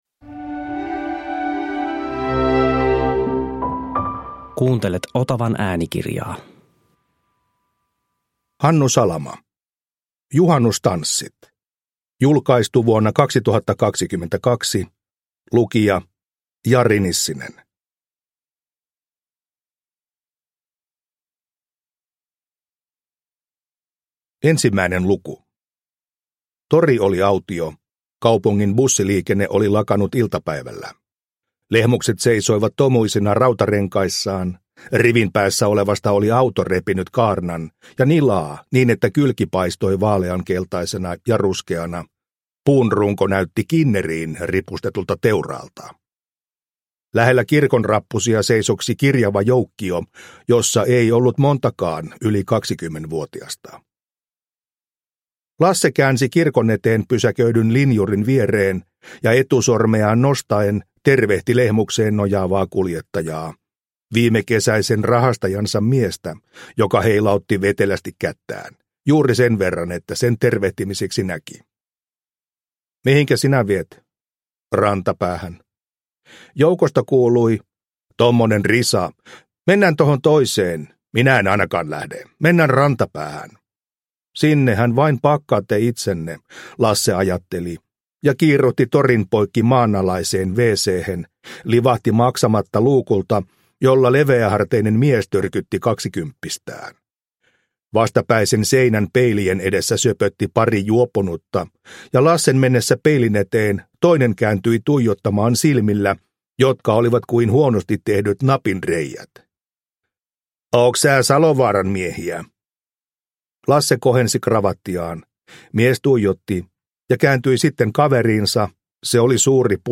Juhannustanssit – Ljudbok – Laddas ner